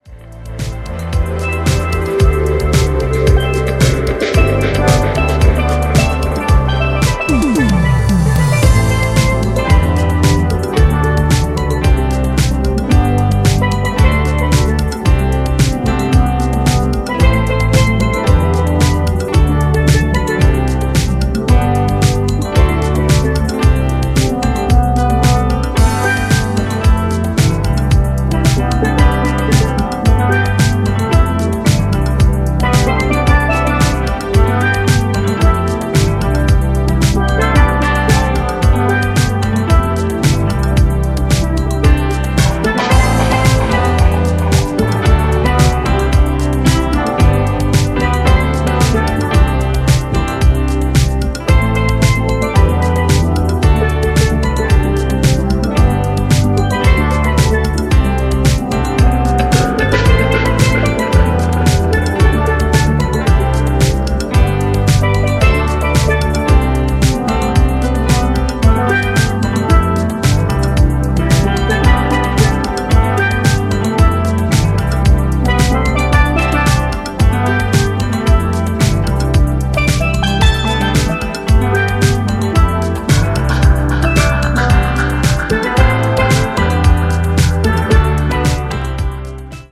Number 5 features a steel band cover of an 80’s classic